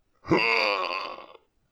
zombie_death_real.wav